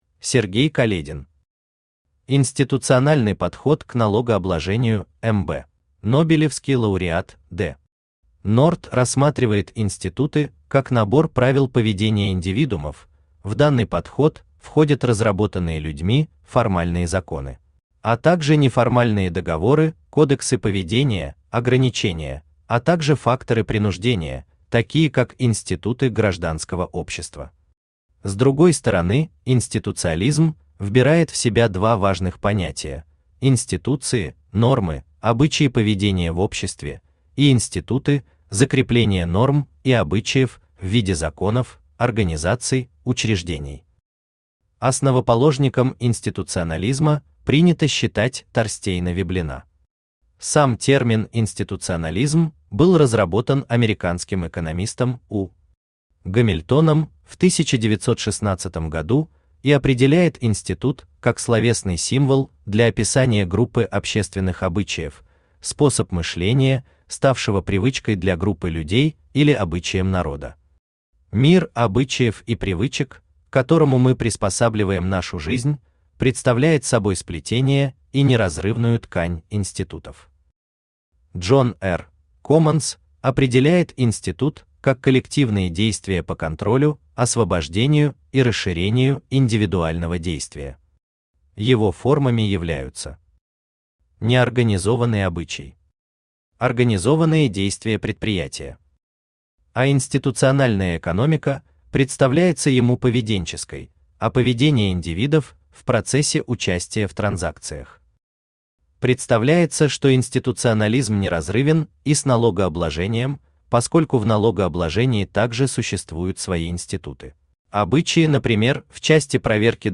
Аудиокнига Институциональный подход к налогообложению МБ | Библиотека аудиокниг
Читает аудиокнигу Авточтец ЛитРес.